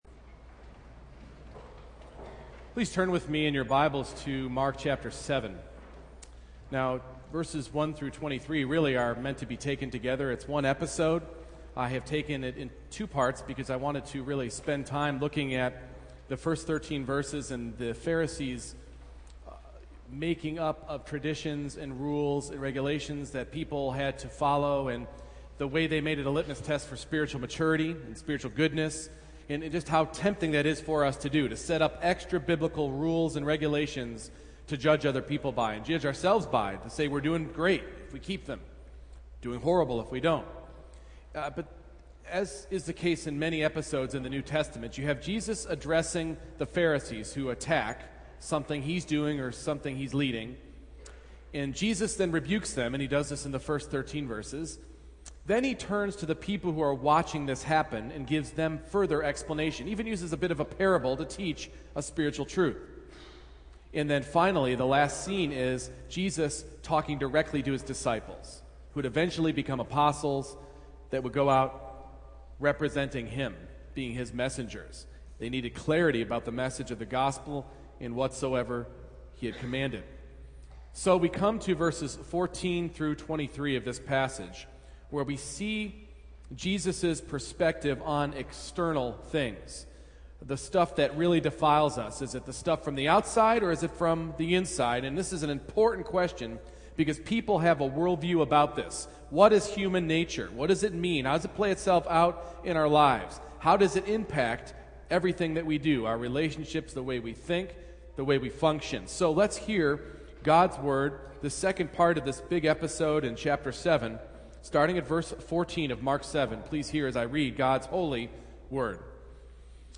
Mark 7:14-23 Service Type: Morning Worship Many believe you can effect change in people by a change on the outside.